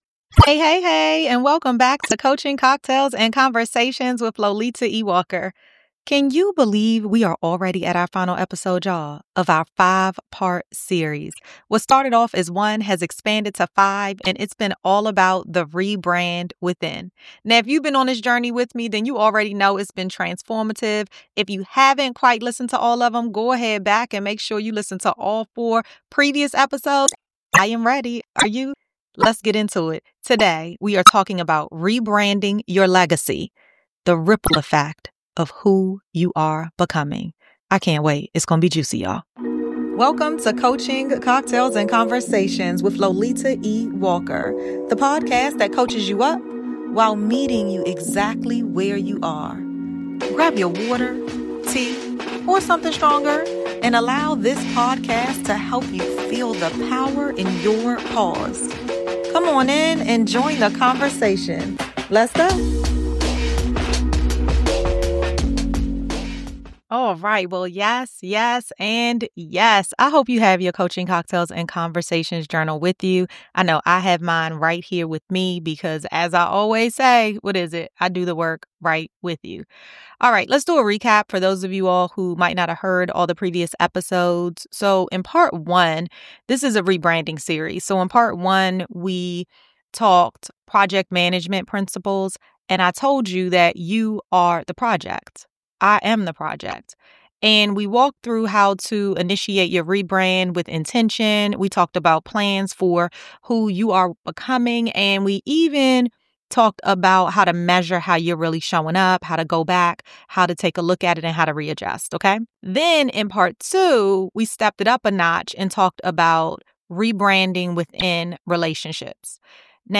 1 Community Table: Client Relations From First Call to Alternative Fee and Payment Methods 26:44 Play Pause 10d ago 26:44 Play Pause Play later Play later Lists Like Liked 26:44 In this episode’s discussions around the Community Table, recorded in person at the October 2025 ClioCon legal tech conference: After a new client comes on board, within a week, your team needs to check back in. This is your chance to delight.